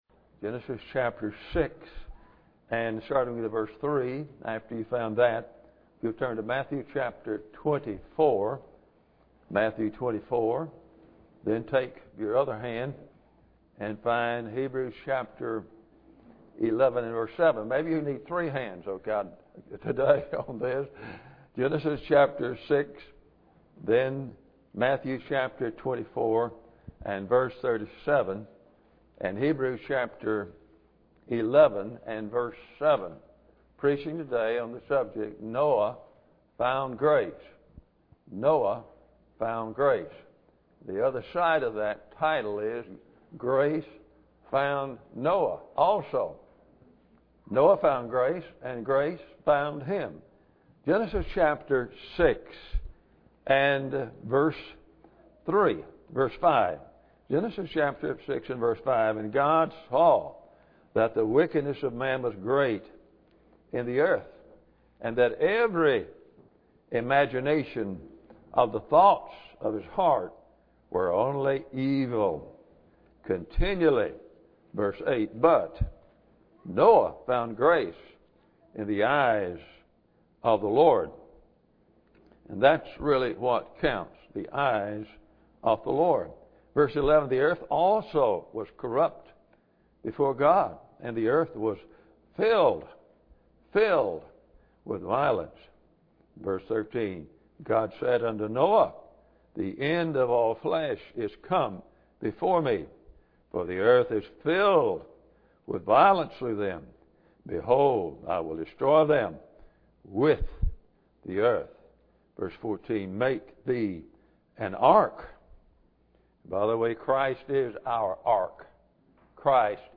Genesis 6:3-14 Service Type: Sunday Morning Bible Text